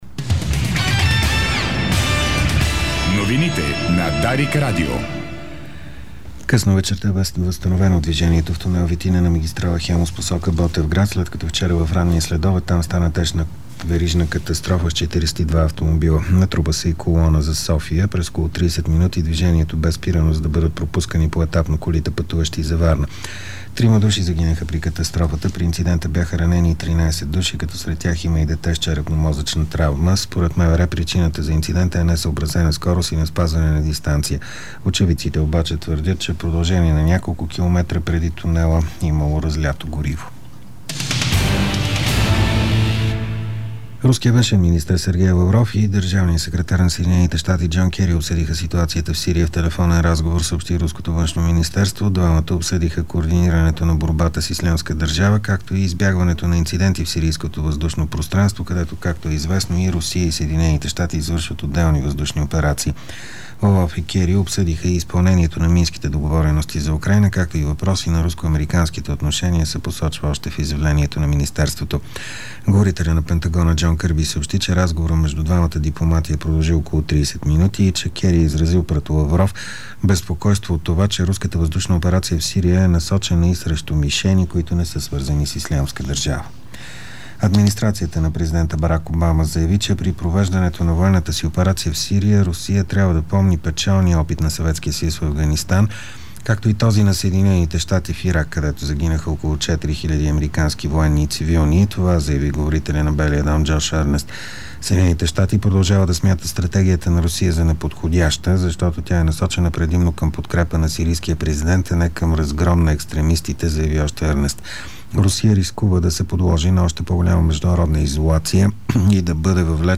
Новините в аудио 09.10.2015